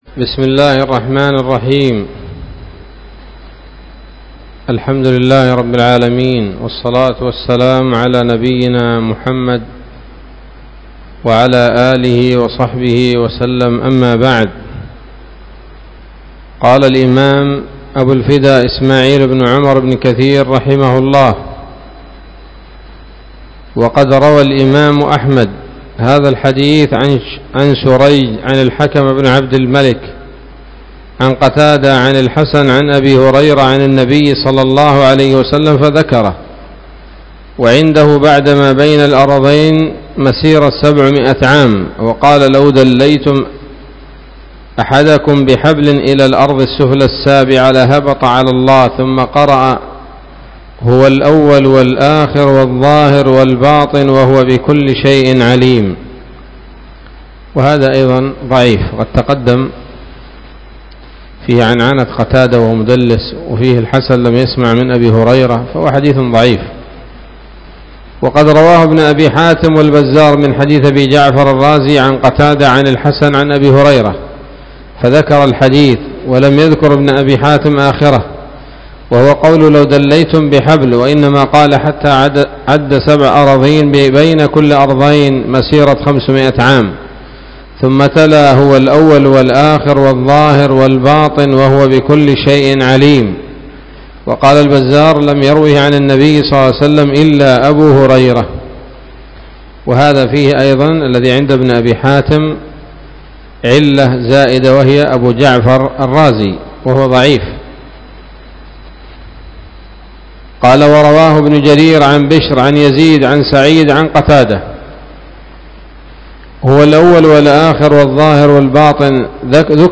الدرس الثاني من سورة الحديد من تفسير ابن كثير رحمه الله تعالى